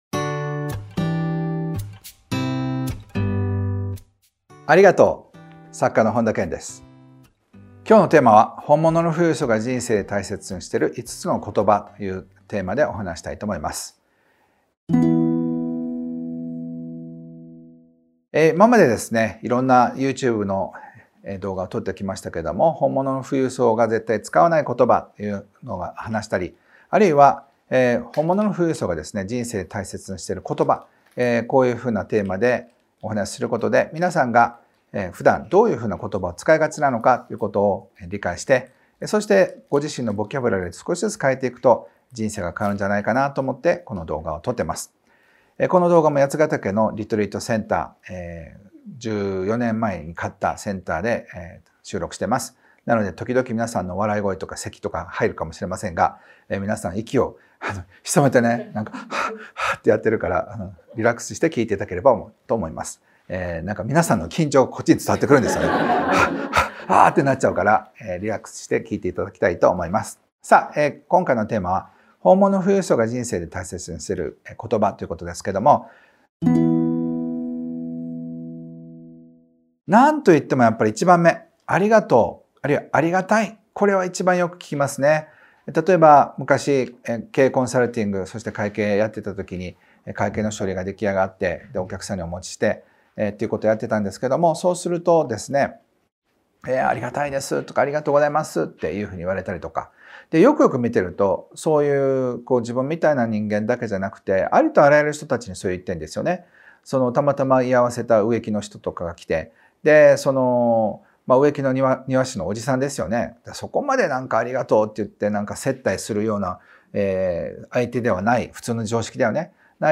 本編の収録を終えたあとの、素顔のトークをお届けする「オフトーク」シリーズがスタート。
収録現場のリアルな空気感をお楽しみください。